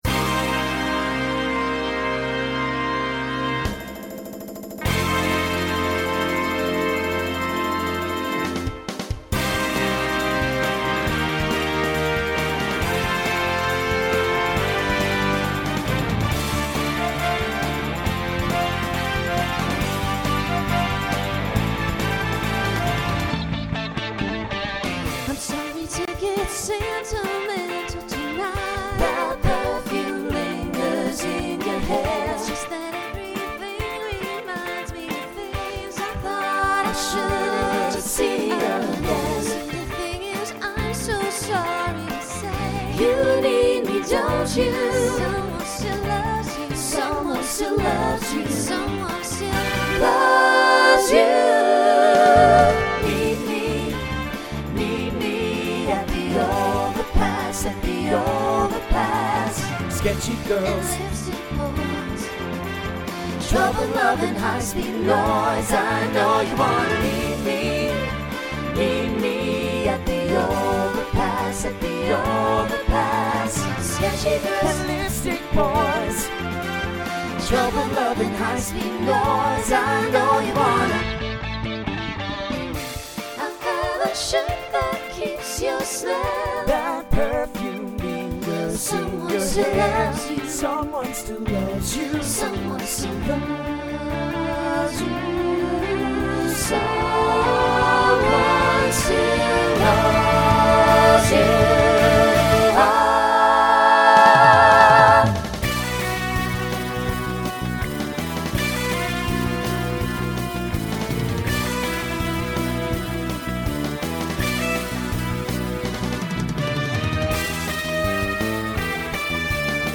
New SSA voicing for 2020
SSA Instrumental combo Genre Rock Decade 1990s